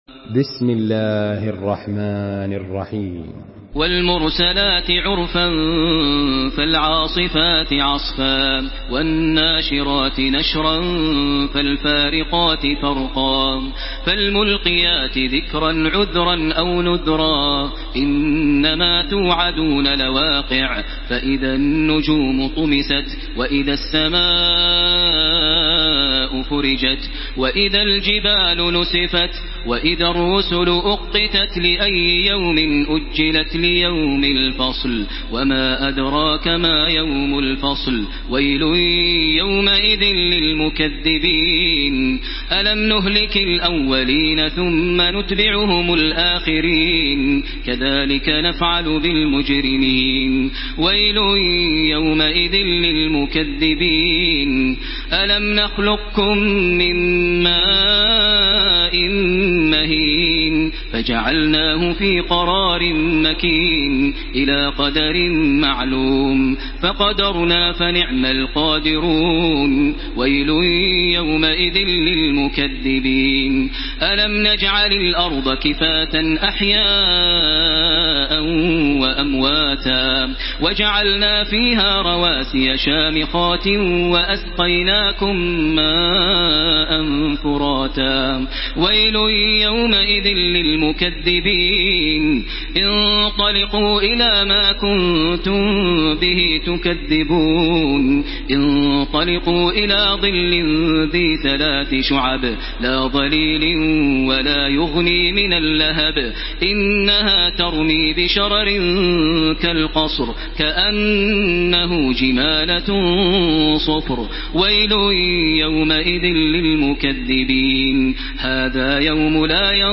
Surah Al-Mursalat MP3 in the Voice of Makkah Taraweeh 1434 in Hafs Narration
Murattal